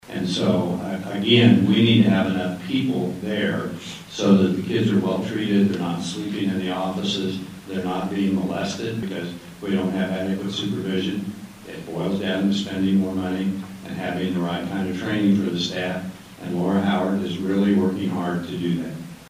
MANHATTAN — Hurdles to Medicaid expansion were a major highlight of Saturday’s legislative coffee held at the Sunset Zoo Nature Exploration Place.